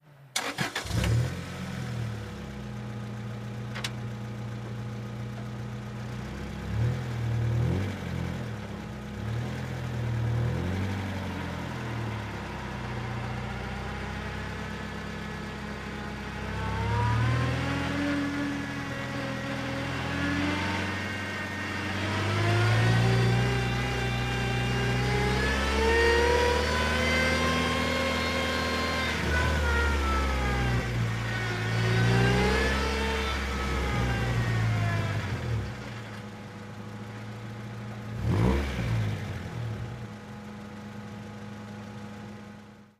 Integra onboard - engine, start, idle, pull away